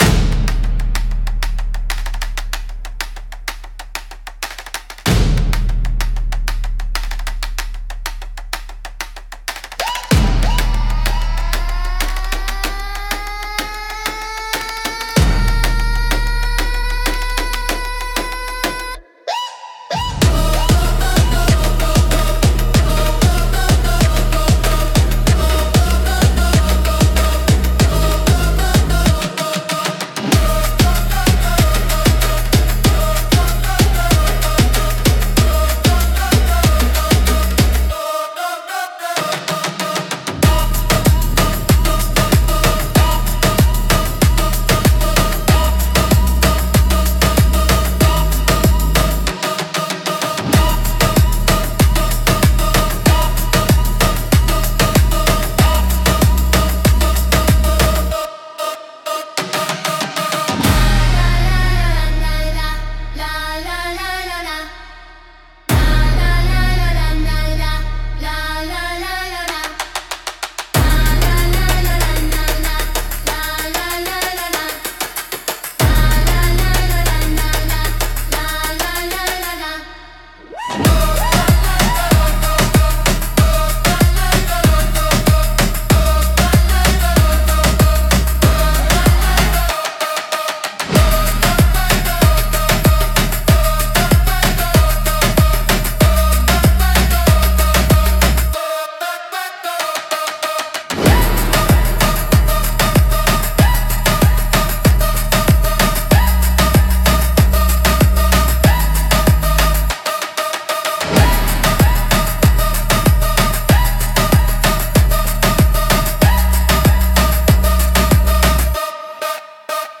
迫力と神秘性が共存するジャンルです。